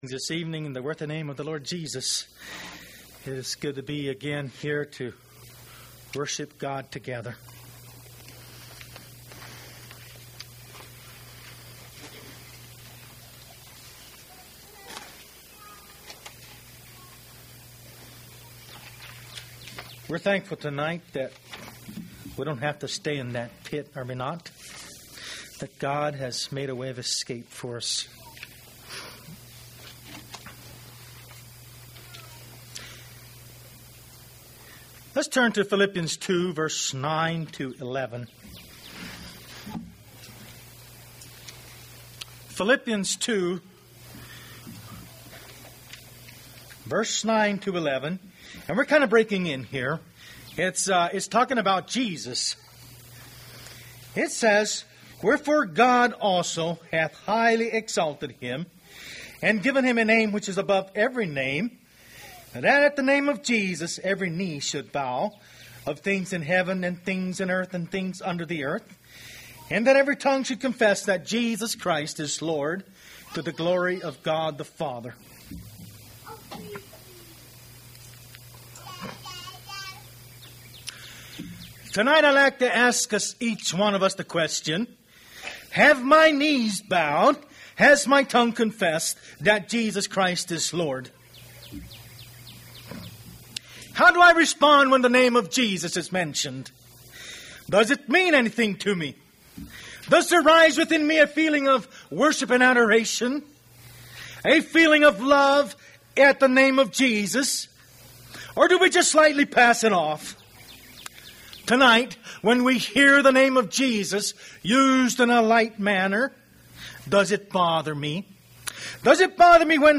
Sermons
Ridge View | Tent Meetings 2023